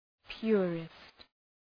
Προφορά
{‘pjʋərıst}